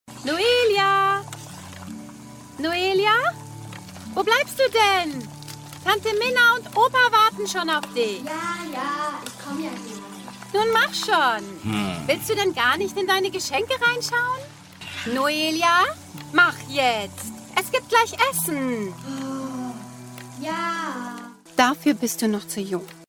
Erzählung